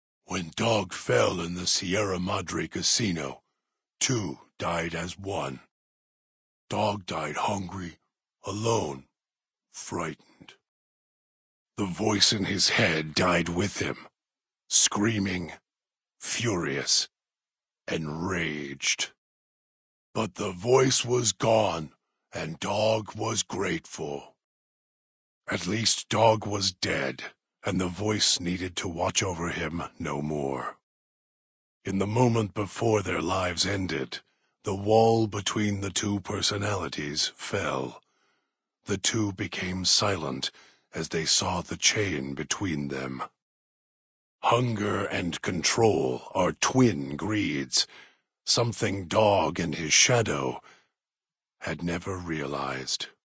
Category:Dead Money endgame narrations Du kannst diese Datei nicht überschreiben. Dateiverwendung Die folgende Seite verwendet diese Datei: Enden (Dead Money) Metadaten Diese Datei enthält weitere Informationen, die in der Regel von der Digitalkamera oder dem verwendeten Scanner stammen.